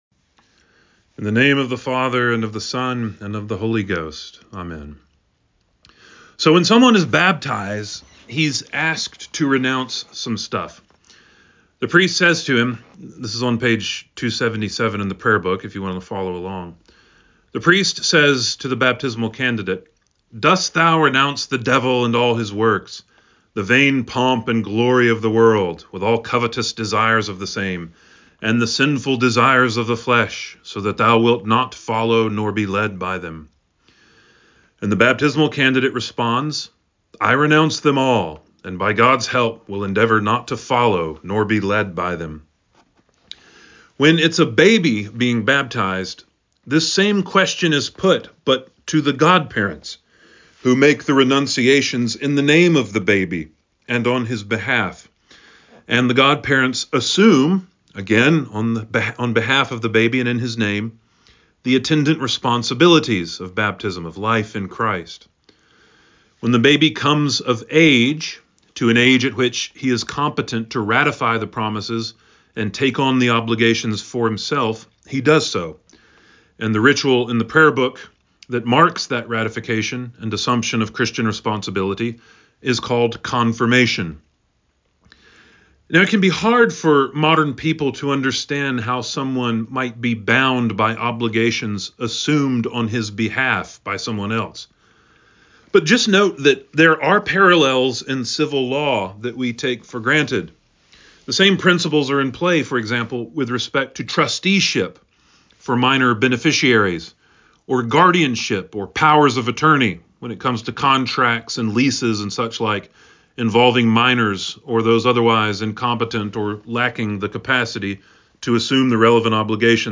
Sermon for the Third Sunday in Lent 03.23.25